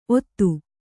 ♪ ottu